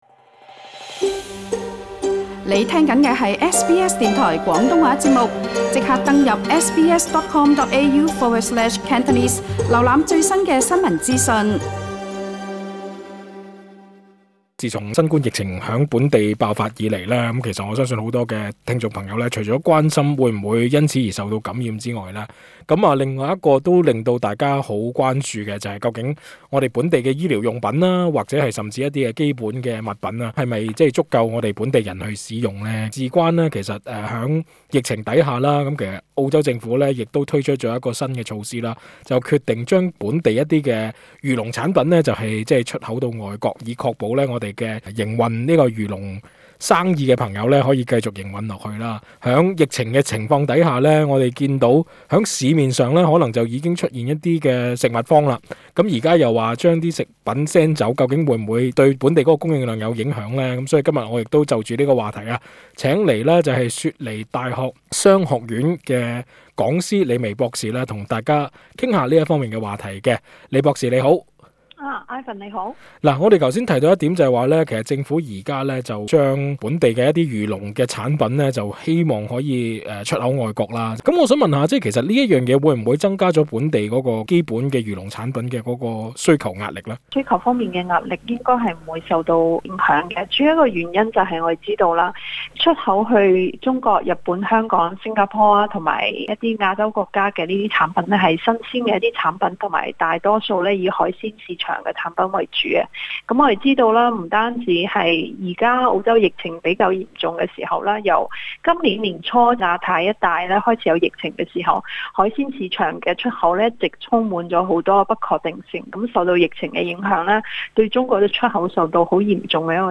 更多詳情，請留意訪問的足本錄音。